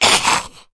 troll_mage_damage.wav